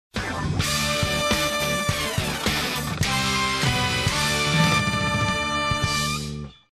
Spiel ankündigung
schlag-den-raab-sound-spieleankundigung.mp3